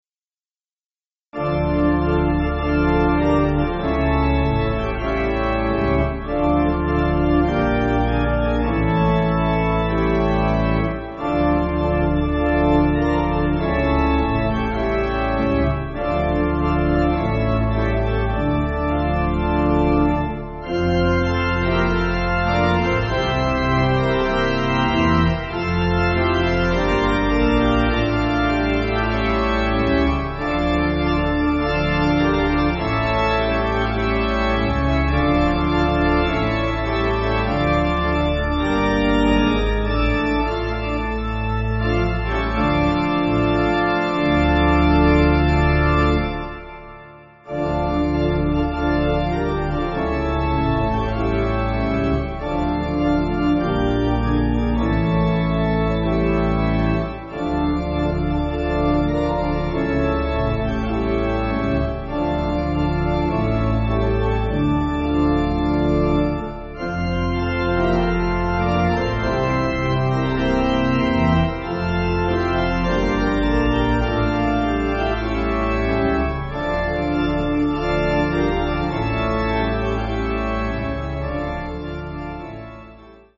Organ
(CM)   4/Dm